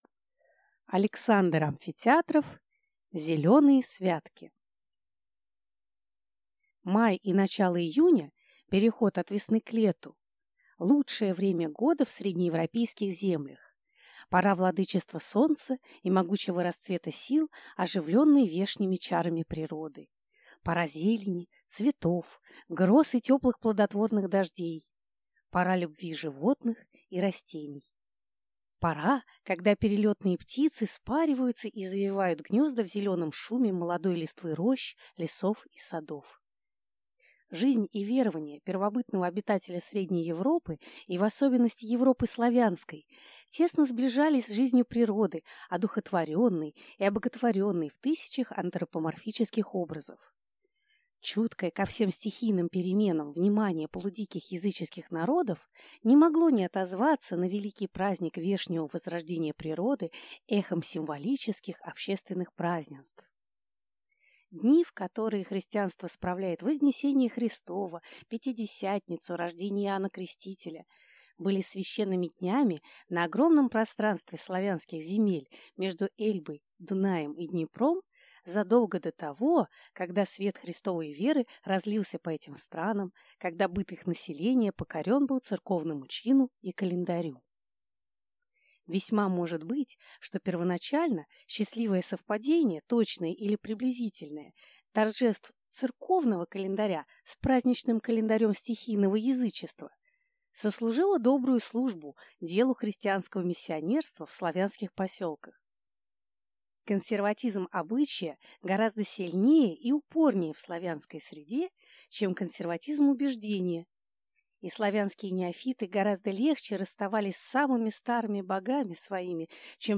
Аудиокнига Зеленые святки | Библиотека аудиокниг
Прослушать и бесплатно скачать фрагмент аудиокниги